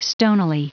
Prononciation du mot stonily en anglais (fichier audio)
Prononciation du mot : stonily